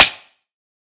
Index of /99Sounds Music Loops/Drum Oneshots/Twilight - Dance Drum Kit/Claps